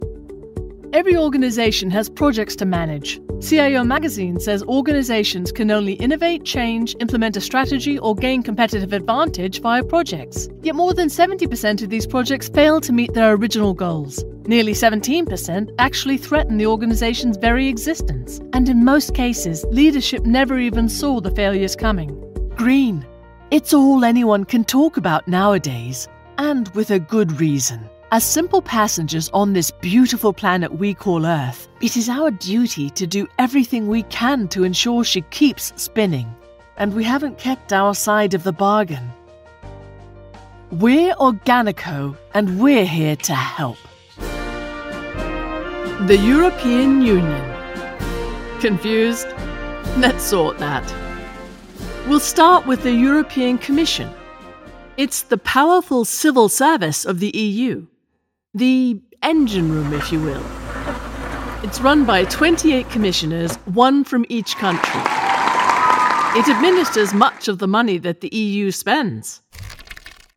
Explainer Videos
I'm a voice actor with a native British accent.
- Recording booth: Whisperroom 5x5
- Microphones: Neumann TLM-103
Contralto
ConversationalFriendlyAuthoritativeWarmExperiencedAssuredEngagingTrustworthyConfidentRelatable